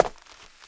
HARDWOOD 2.WAV